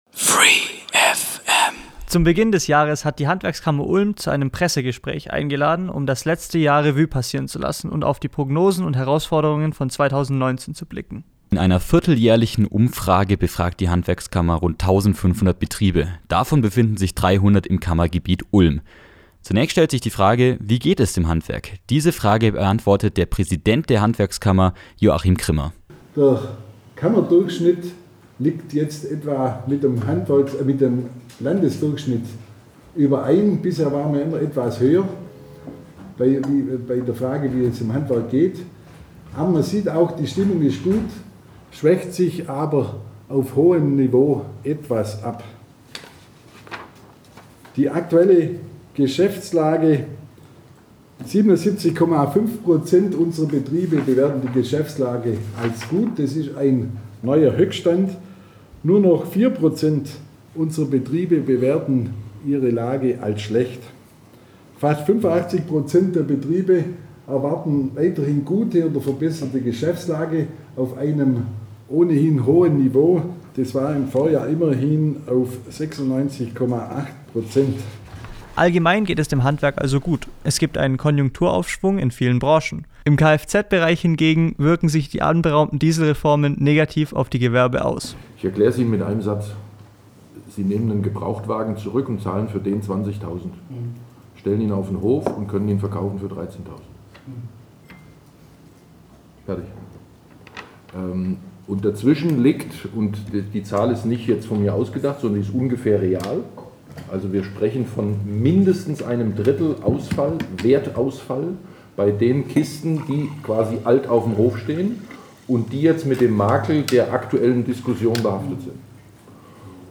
Um diese auch zu beantworten, hat die Handwerkskammer Ulm zu einem Pressegespräch eingeladen.